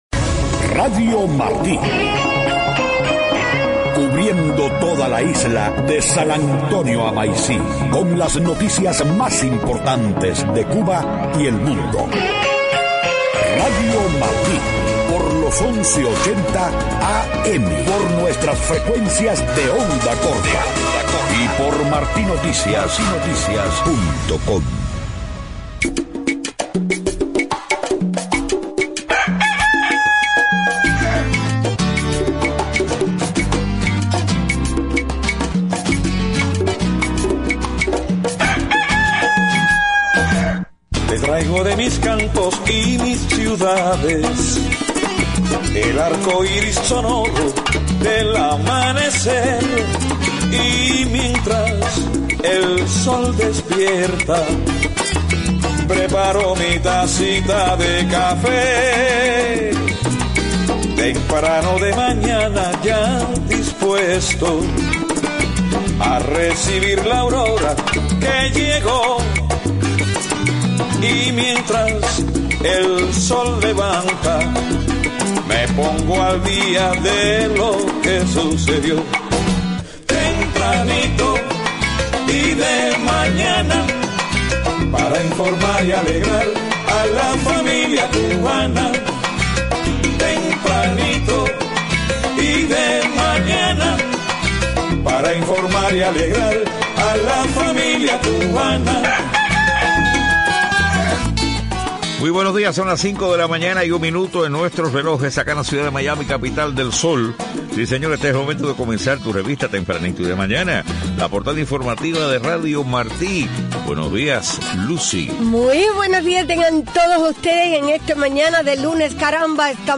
5:00 a.m. Noticias: Transcurre otro domingo más de arrestos y represiones contra las Damas de Blanco y activistas en La Habana. La oposición venezolana convoca a una movilización para el 8 de agosto por la libertad, contra el hambre y el hampa.